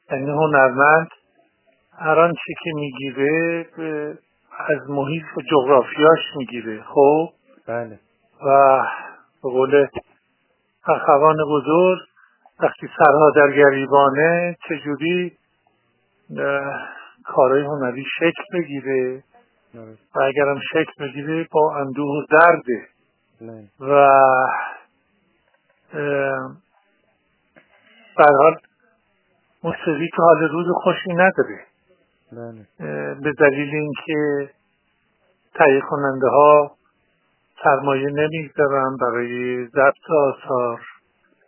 بیژن بیژنی در گفت‌وگو با ایکنا:‌